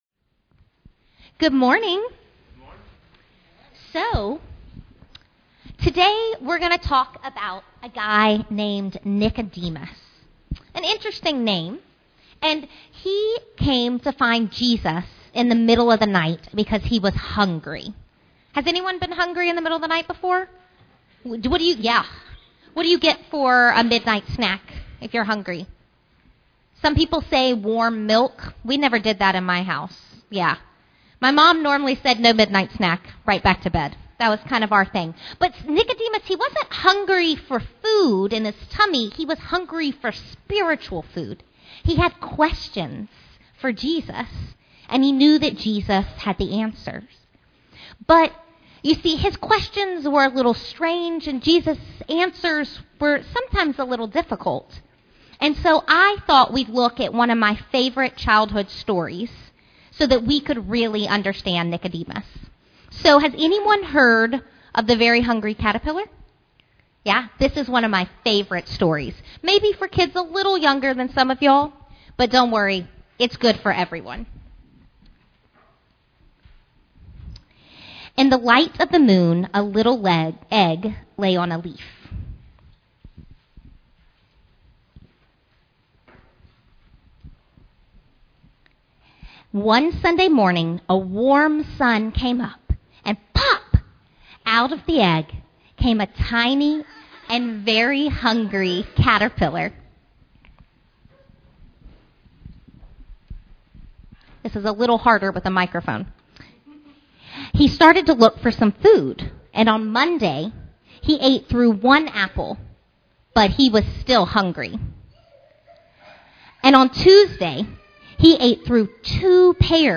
Home Sermons Children's Sermon Hungry Caterpillar Hungry Caterpillar What happens to the Caterpillar when it eat too much?